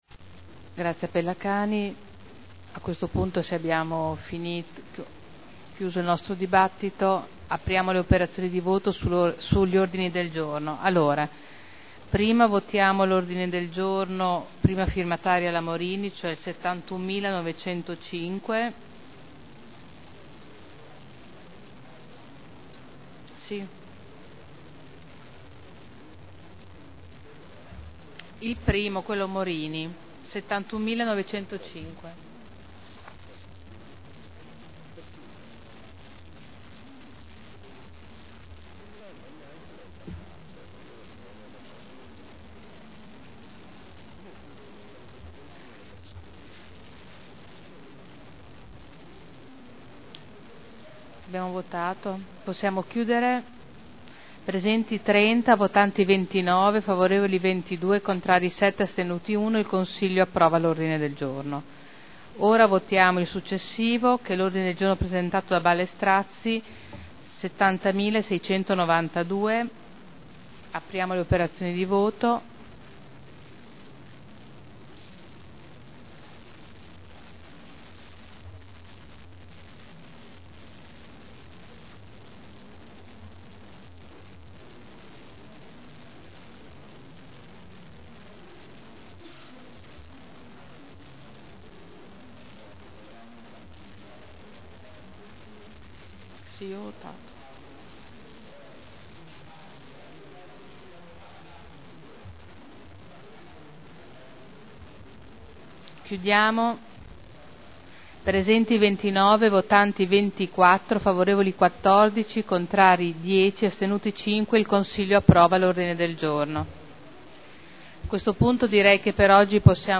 Presidente — Sito Audio Consiglio Comunale
Seduta del 24/01/2011.